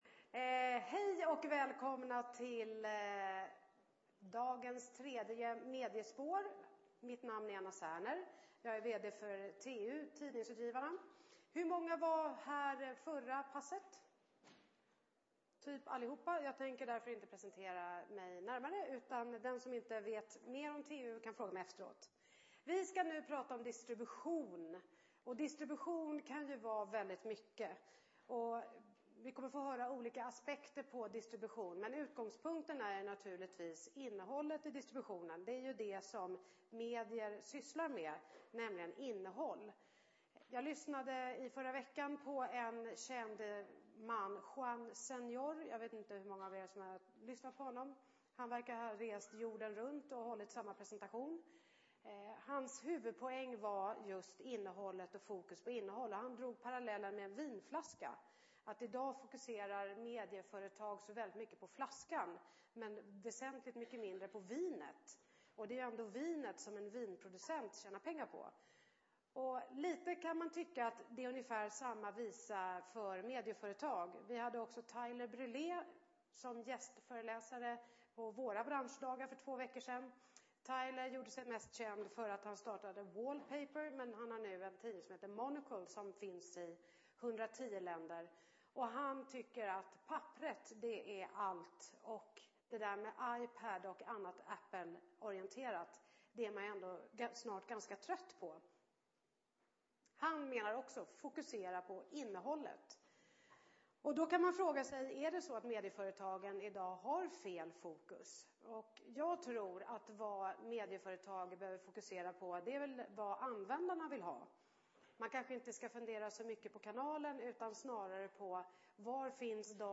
Distributionen: Många vägar till publiken Plats: Kongresshall B Datum: 2010-10-26 Tid: 15:30-17:00 Distributionen, den fysiska länken mellan producent och konsument, har revolutionerats på flera sätt av utvecklingen.